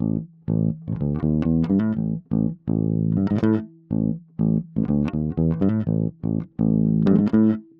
08 Bass PT2.wav